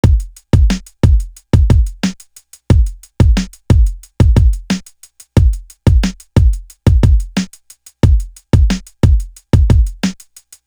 Different Worlds Drum.wav